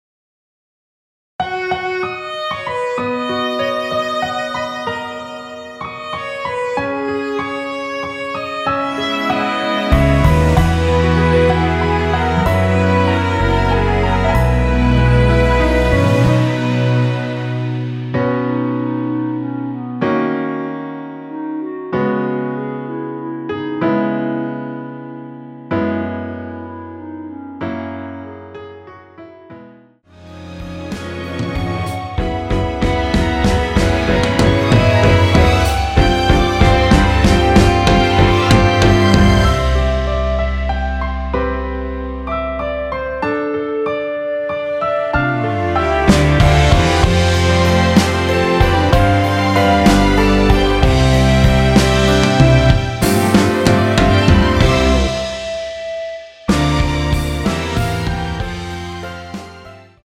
원키 멜로디 포함된 MR입니다.
앞부분30초, 뒷부분30초씩 편집해서 올려 드리고 있습니다.
(멜로디 MR)은 가이드 멜로디가 포함된 MR 입니다.